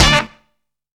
BOOGIE STAB.wav